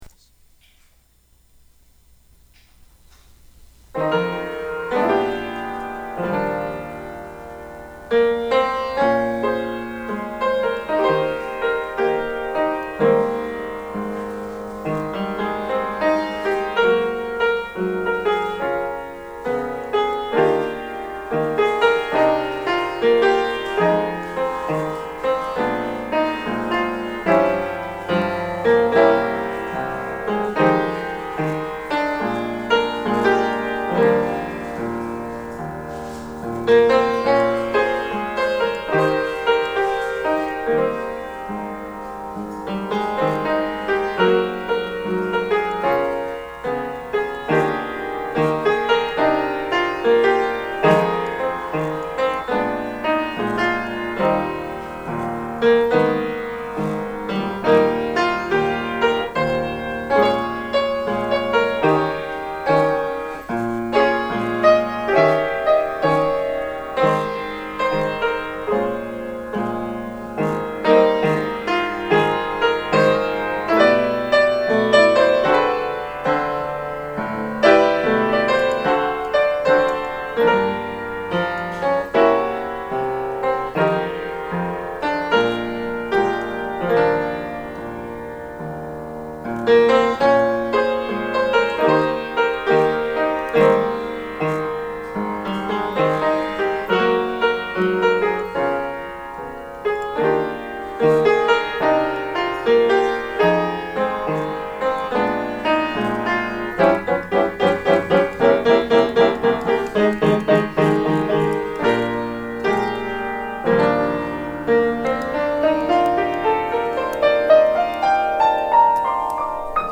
mostly beginners